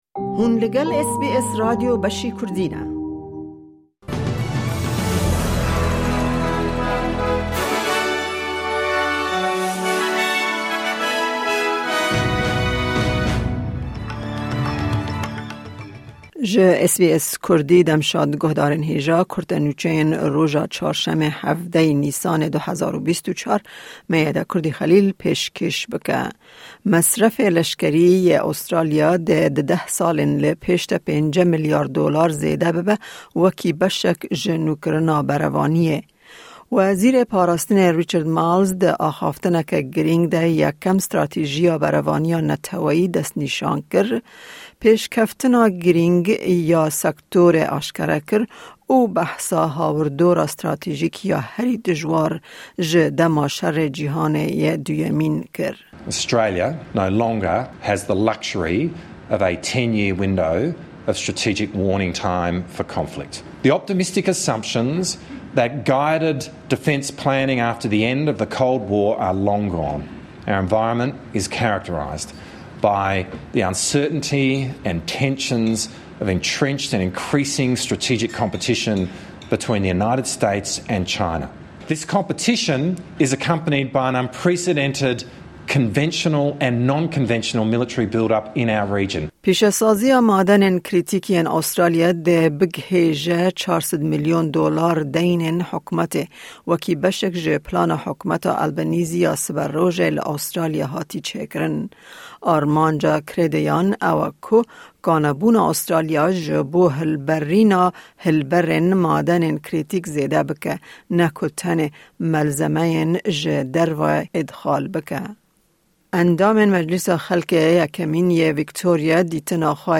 Kurte Nûçeyên roja Çarşemê 17î Nîsana 2024